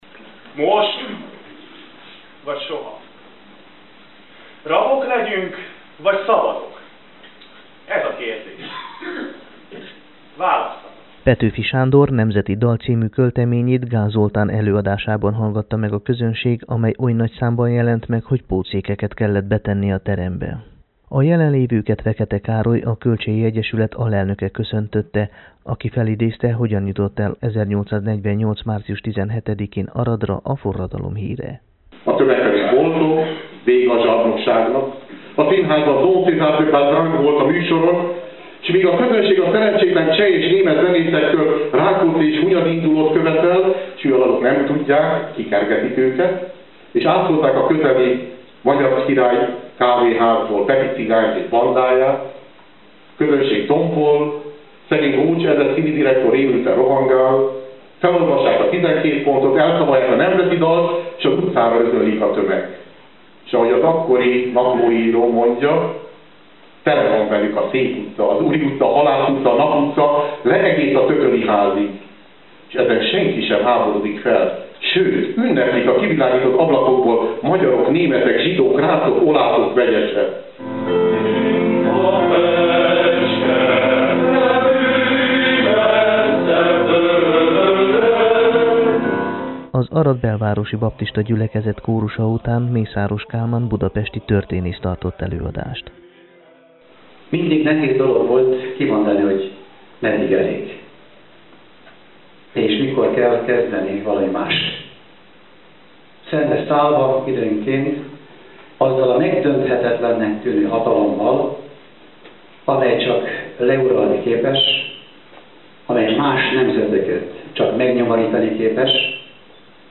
Az 1848-as magyar forradalom kitörésének 167. évfordulója alkalmából szervezett aradi emlékünnepségek sora szombat délután kezdődött.